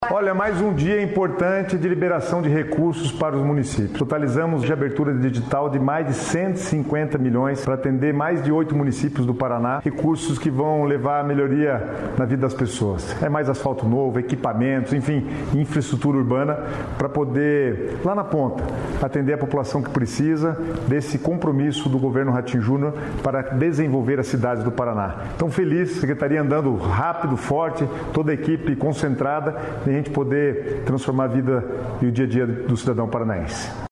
Sonora do secretário das Cidades, Guto Silva, sobre o programa Asfalto Novo, Vida Nova